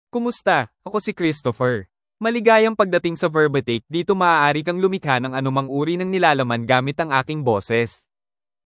MaleFilipino (Philippines)
ChristopherMale Filipino AI voice
Christopher is a male AI voice for Filipino (Philippines).
Voice sample
Listen to Christopher's male Filipino voice.
Male
Christopher delivers clear pronunciation with authentic Philippines Filipino intonation, making your content sound professionally produced.